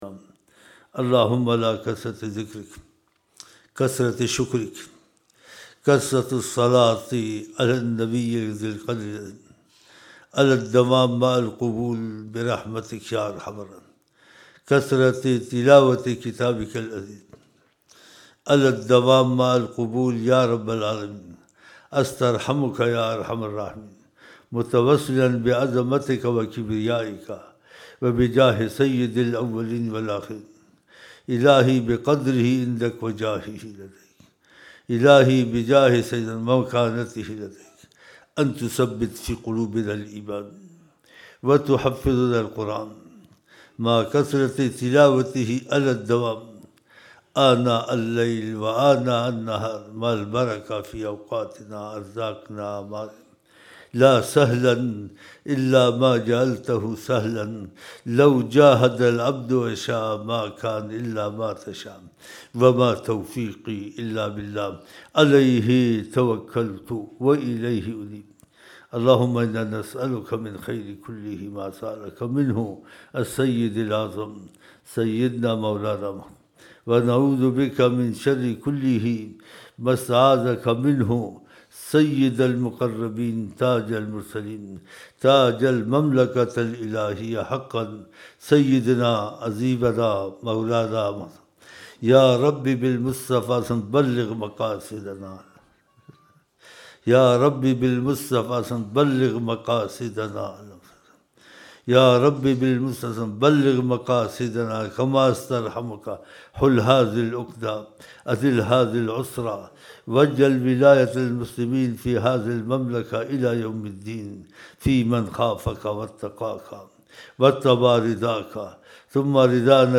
06 January 2007 Saturday Zohr Mehfil (15 Dhul Hijjah 1427 AH)
Dua – 8 Minute Naatiya Ashaar